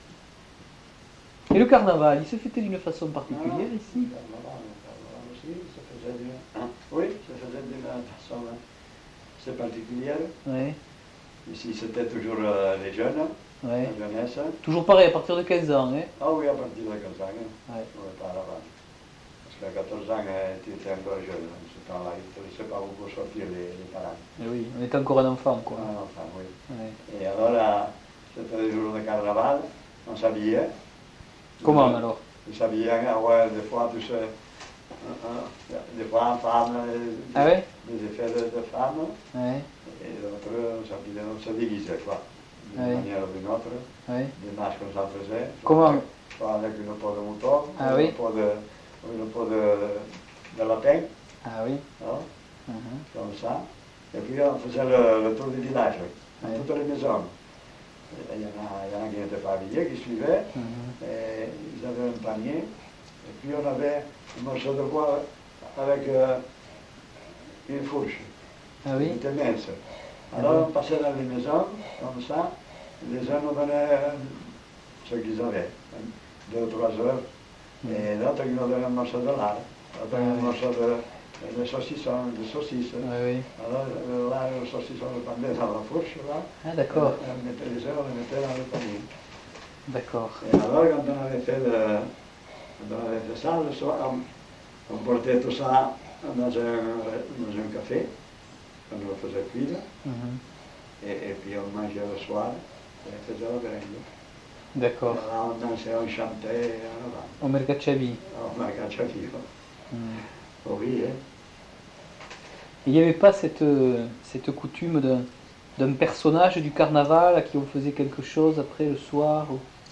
Lieu : Samortein (lieu-dit)
Genre : témoignage thématique